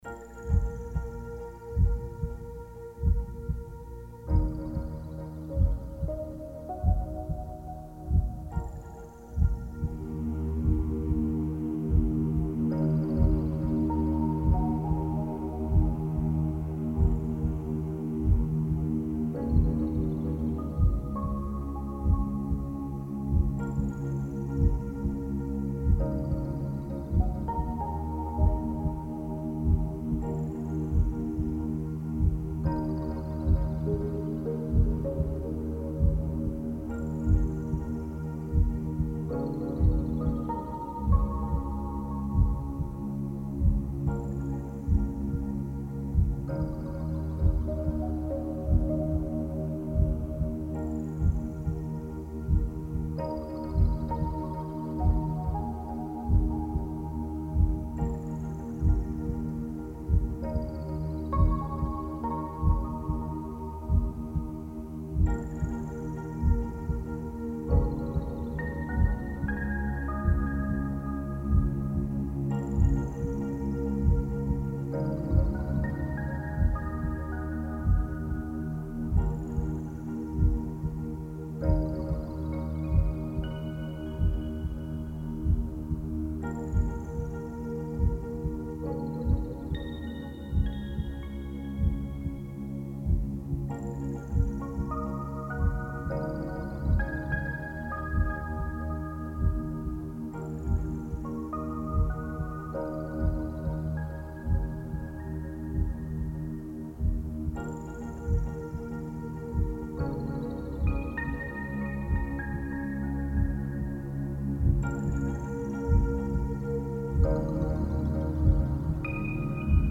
Tempo: 60 bpm / Datum: 01.11.2020